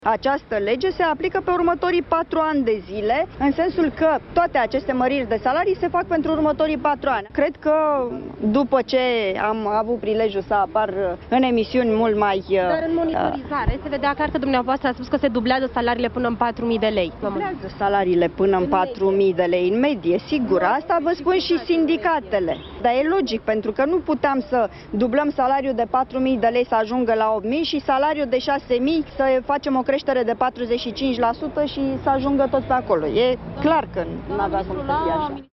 Cât privește declarația privind dublarea salariilor mai mici de 4 mii de lei, Ministrul Muncii și-a nuanțat declarația: